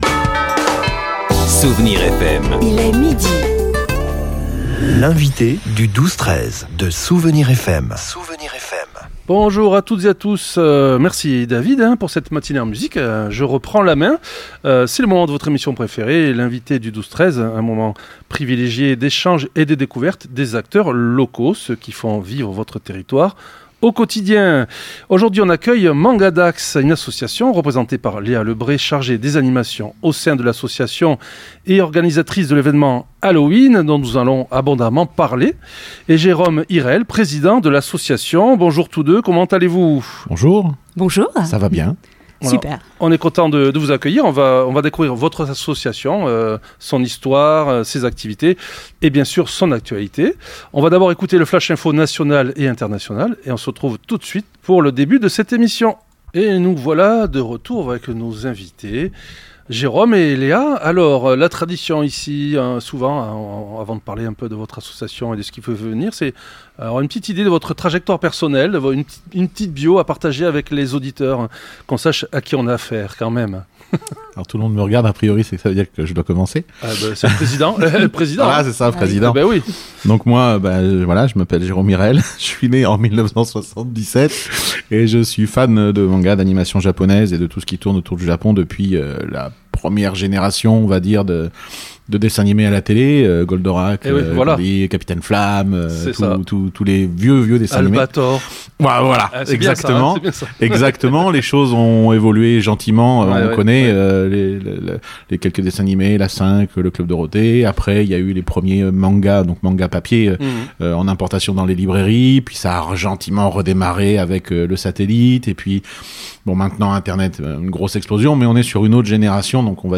Nos invités nous ont présenté leur association, soulignant les liens anciens et profonds entre la culture japonaise et la culture française. C’est tout naturellement qu’est né le besoin de créer un espace local pour rassembler les passionnés et organiser un salon annuel riche en activités : concours de dessins, conférences, cosplay, dégustation d’onigiri, blind test… et bien d’autres surprises !